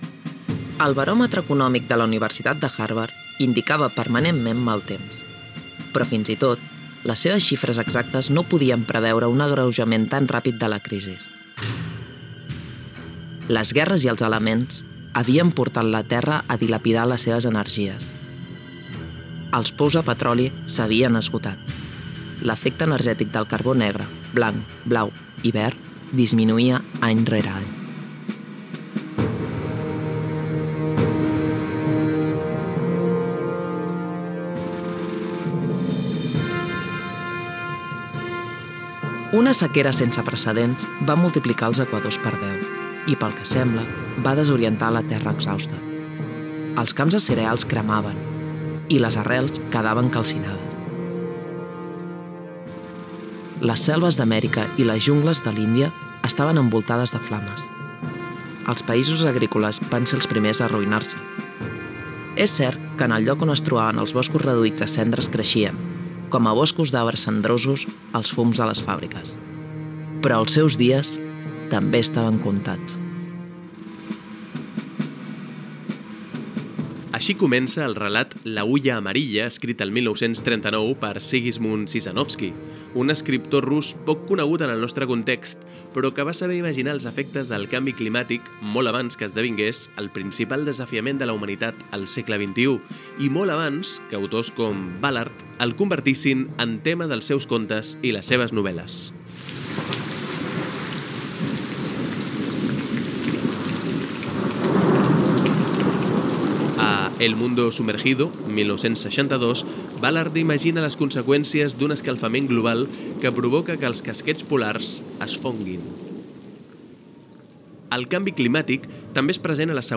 El canvi climàtic és també un gènere literari. Indicatiu del programa.